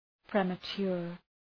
Προφορά
{,premə’tʃʋər}